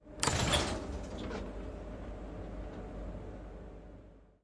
火车 " 倾斜列车门打开2A
描述：记录倾斜列车上的气动门开口。 使用Zoom H6 XY模块记录。
标签： 滑动 打开 机械 火车 机器 按钮开口 气动
声道立体声